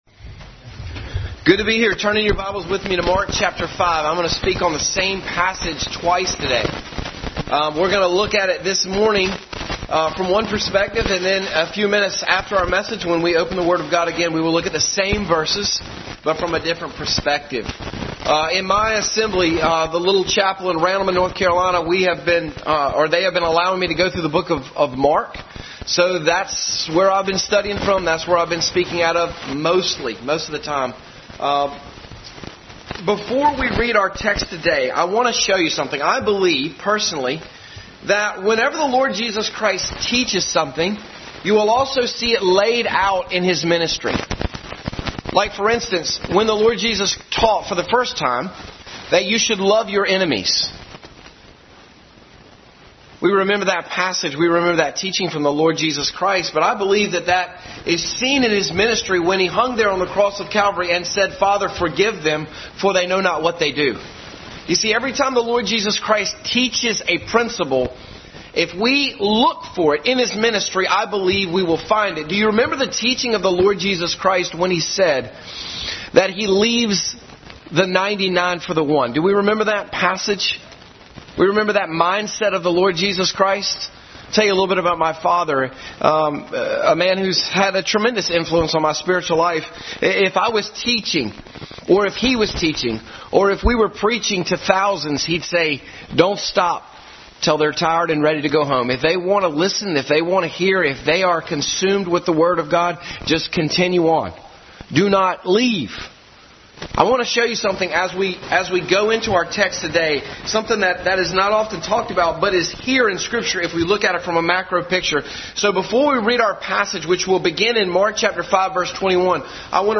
Mark 5:21-43 Passage: Mark 5:21-43, Matthew 9:19 Service Type: Sunday School Adult Sunday School Class.